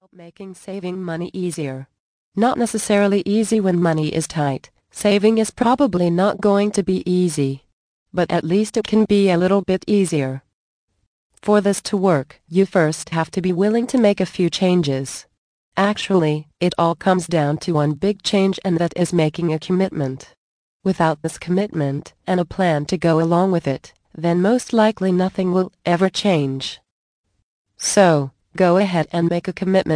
Easy Retirement Planning Tips Audio Book. Vol 4 of 8.